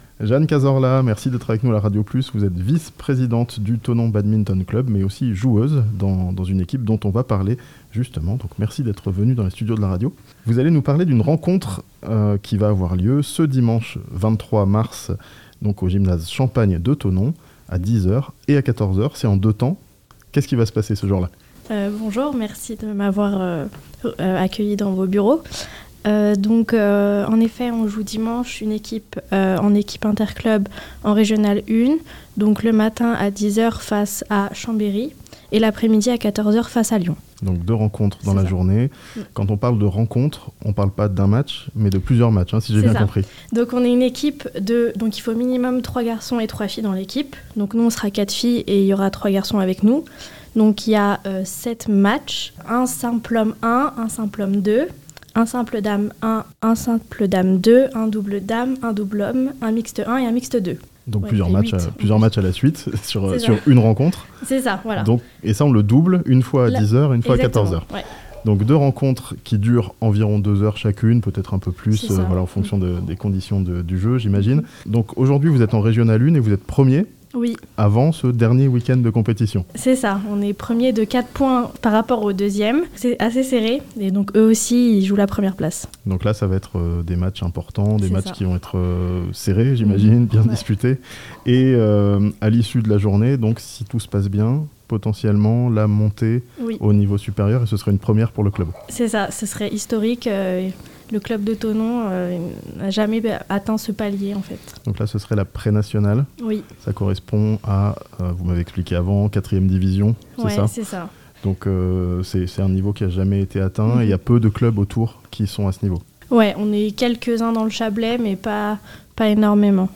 Le Thonon Badminton Club jouera la montée en Prénationale dimanche 23 mars (interview)